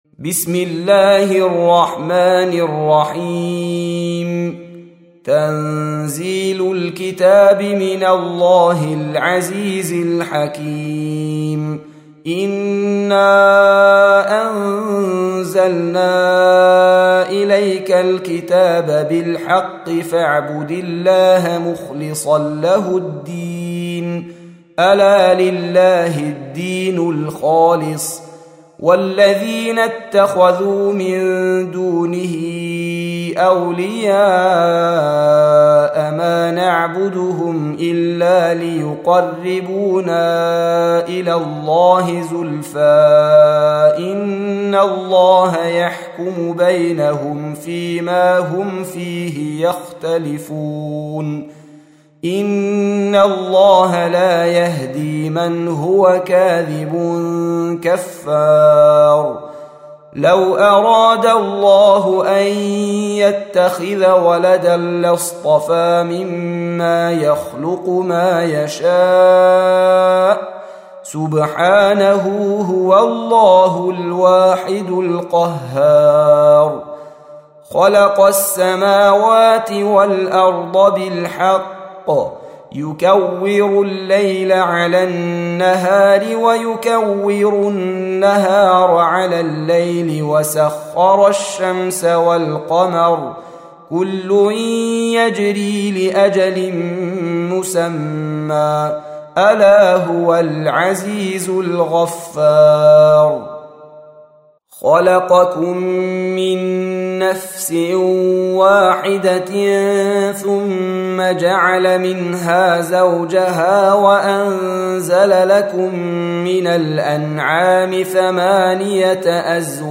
Surah Repeating تكرار السورة Download Surah حمّل السورة Reciting Murattalah Audio for 39.